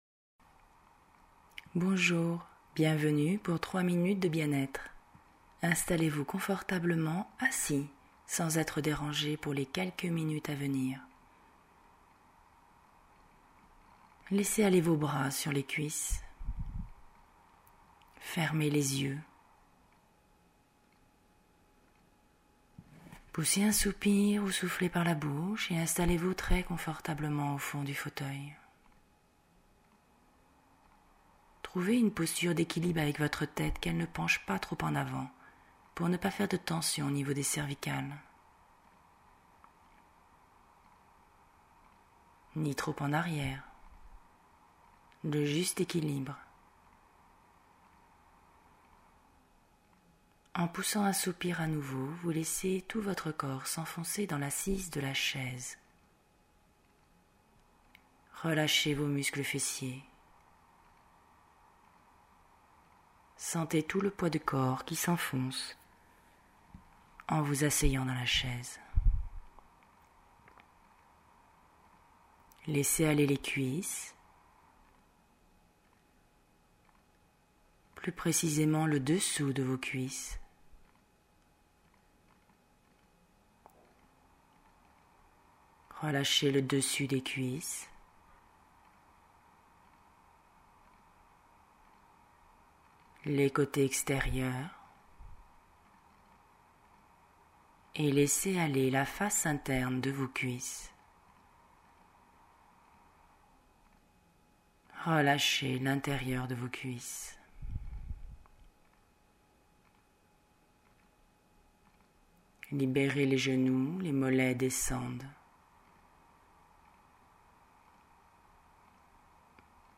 Genre : sophro
relaxation-membres-inferieurs.mp3